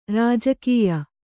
♪ rājakiya